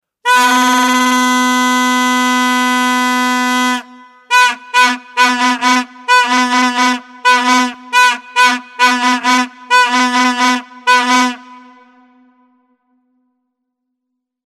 vuvuzela-ringtone_14172.mp3